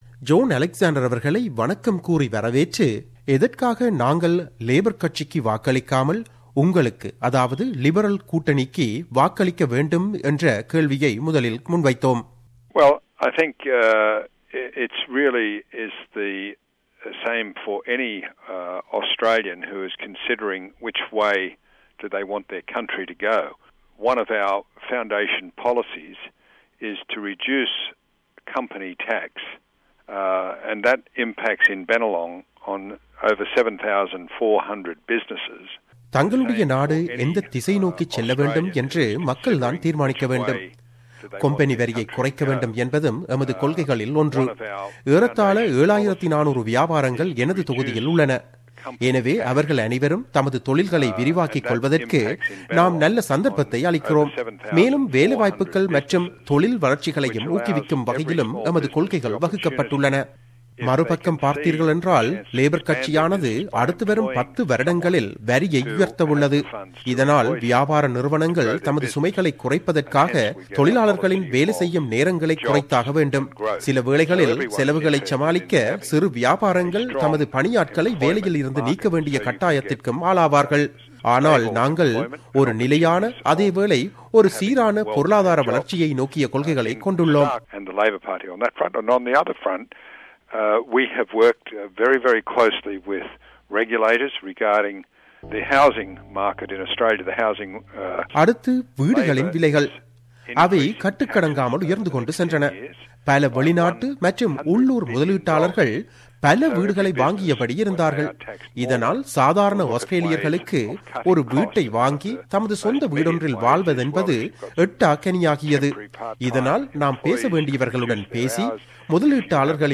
Election 2016: Interview with Liberal MP John Alexander